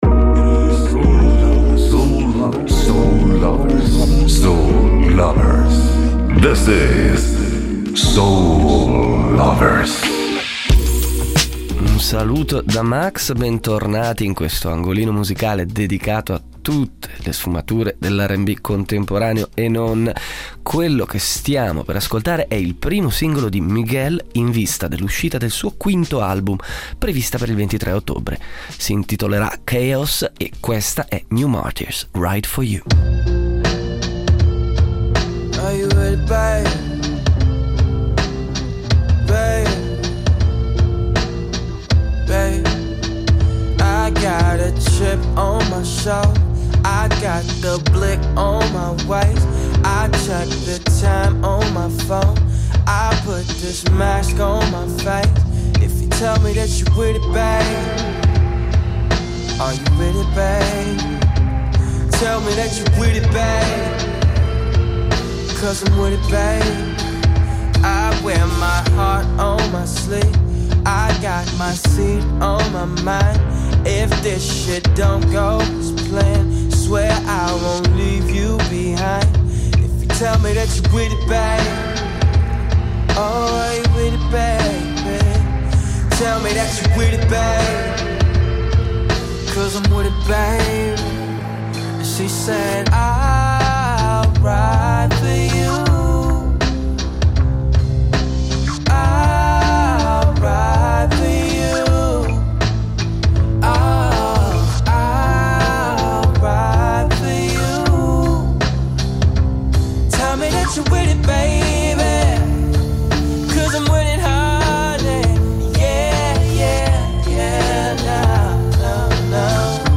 In questa seconda puntata dopo la pausa estiva continuiamo a svuotare lo scatolone delle novità estive mescolandoci qualche uscita più vicina. Tanta Gran Bretagna ma anche una discreta porzione di rnb italiano che non smette mai di sorprendere.